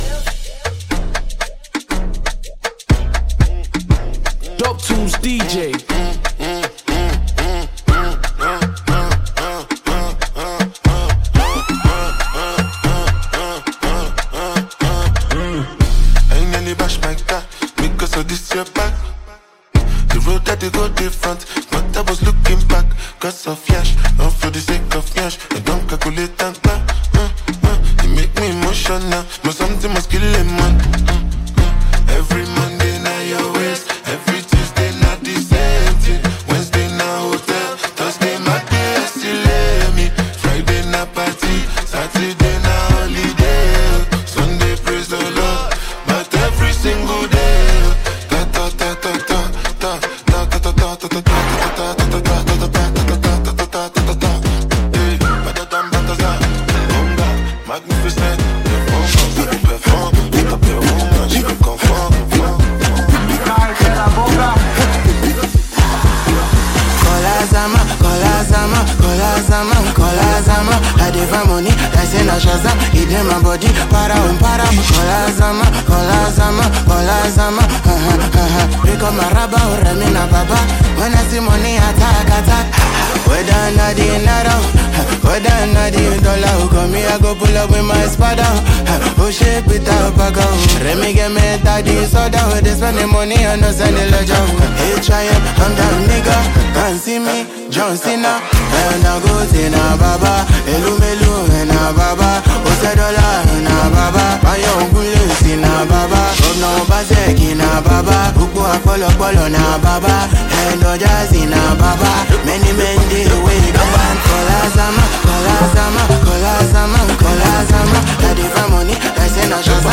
Ghana Afrobeats